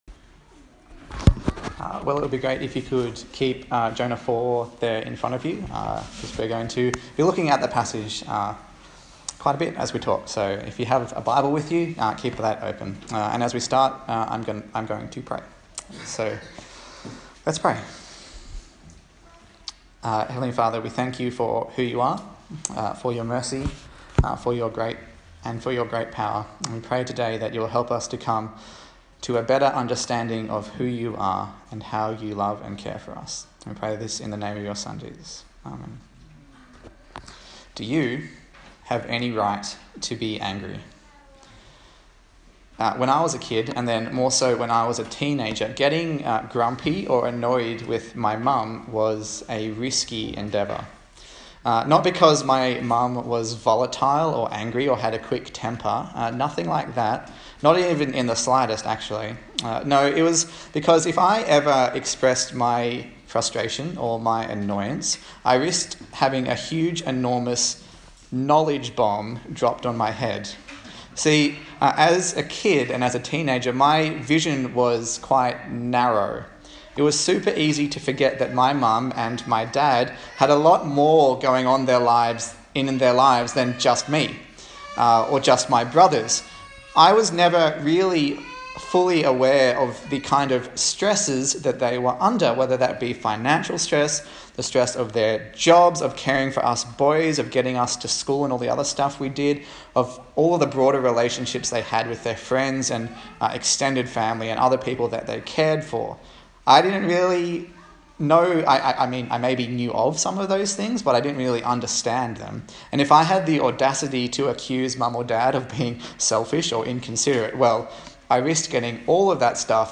Service Type: Sunday Morning A sermon in the series on the book of Jonah